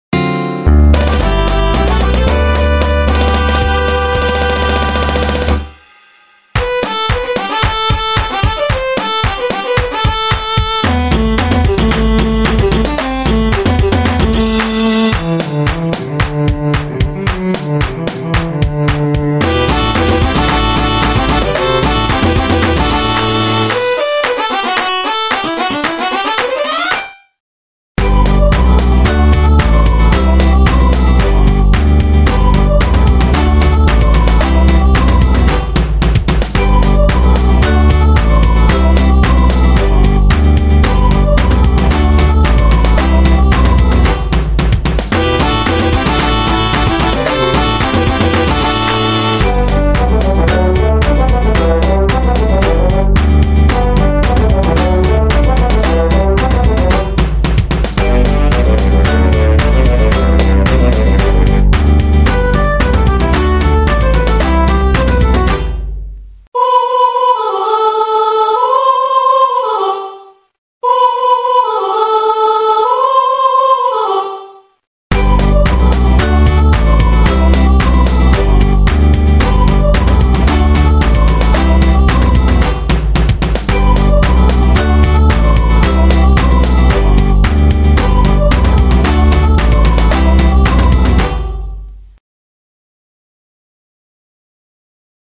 The music is scored for a 16 piece orchestra and at times has 9 individual characters singing together on stage.
Please excuse the vocals on “The Bright Life” tunes being computerized ohs and ahs.
The Bright Life 1-6 Farewell Musical Theater